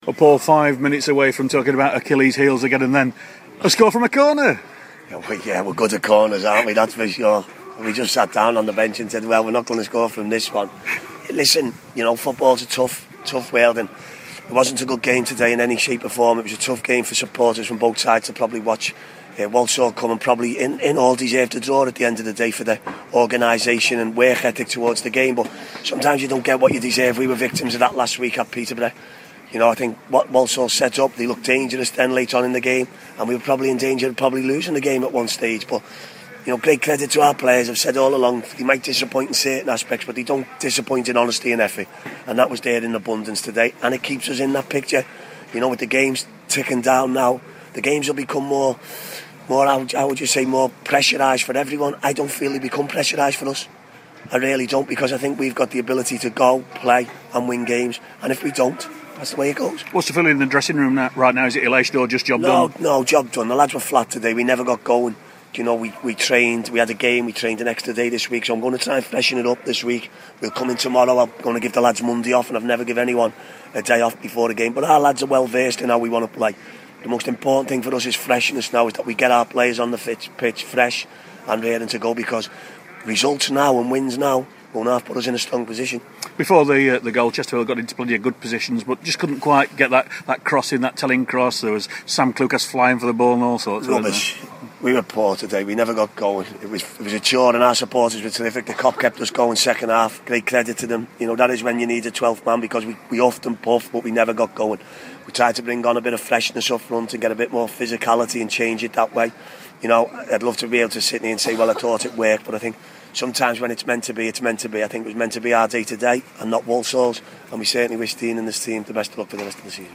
INTERVIEW: Chesterfield Manager Paul Cook after the Spireites 1-0 win over Walsall